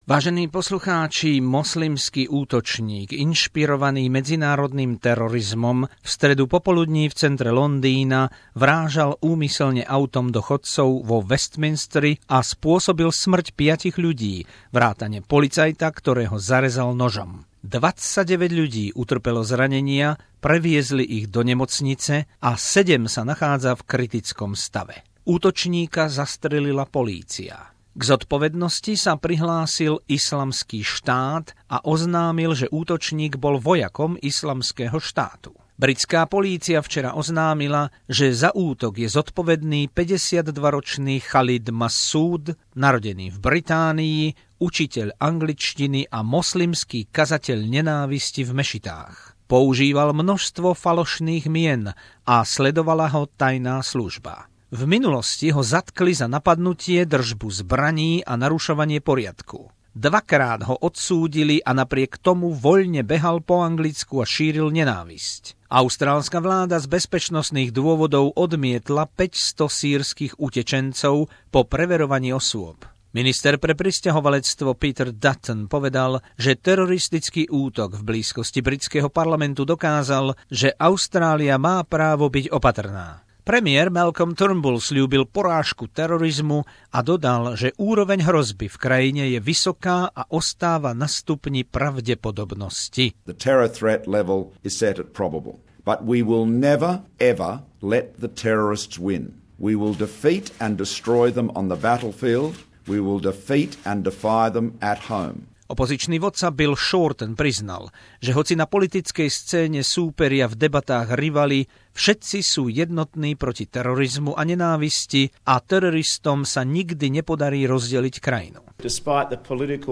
Londýnsky teroristický útok, jeho dôsledky v Austrálii, bezpečnostná situácia v Austrálii a hlasy štyroch politikov - Turnbulla, Shortena, Duttona a Hansonovej